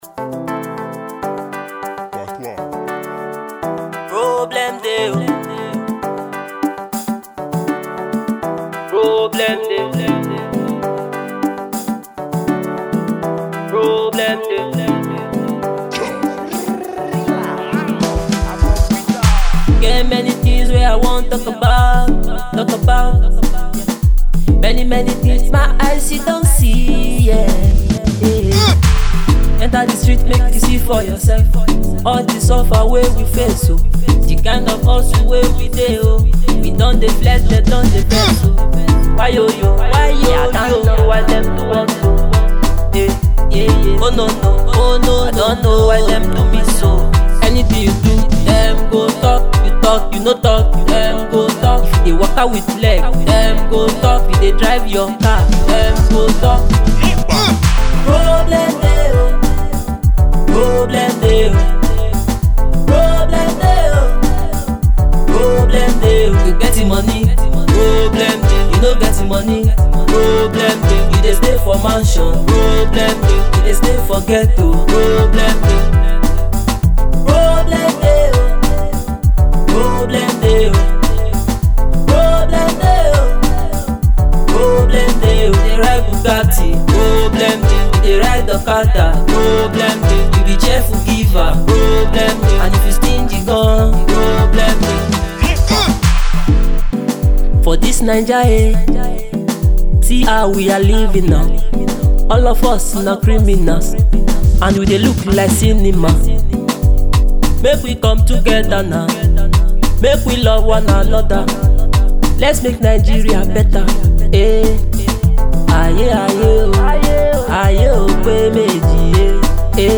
fast raising singer